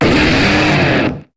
Cri de Roitiflam dans Pokémon HOME.